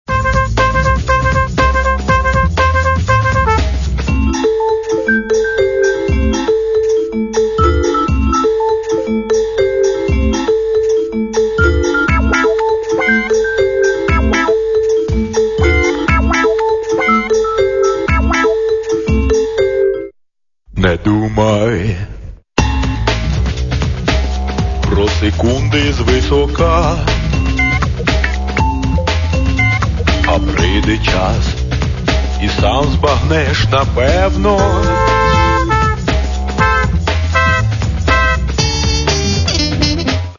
Каталог -> MP3-CD -> Альтернатива